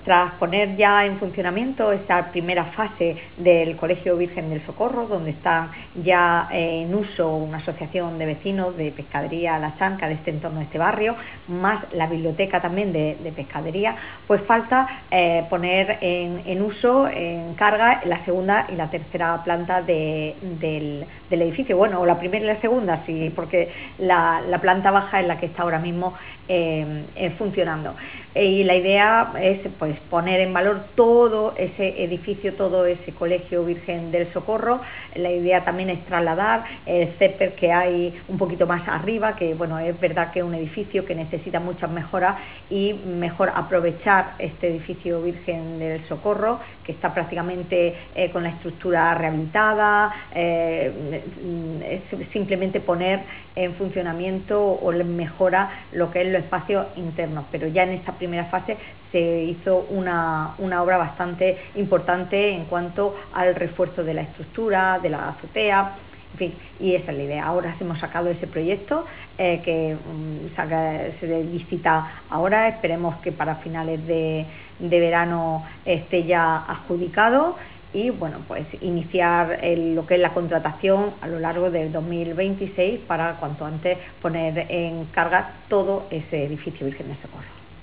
SACRA-SANCHEZ-CONCEJAL-OBRAS-PUBLICAS-SEGUNDA-FASE-REHABILITACION-ANTIGUO-COLEGIO-VIRGEN-DEL-SOCORRO.wav